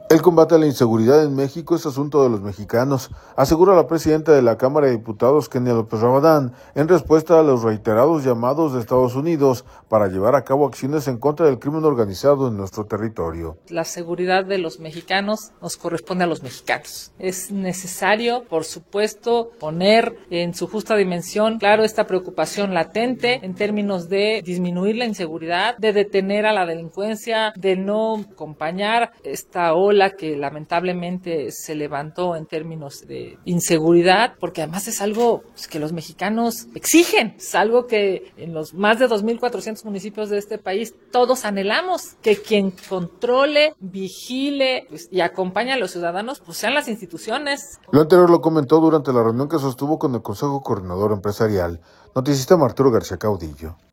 Lo anterior lo comentó durante la reunión que sostuvo con el Consejo Coordinador Empresarial.